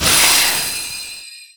Magic_Spell16.wav